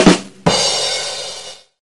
Badumtss Sound Effect Free Download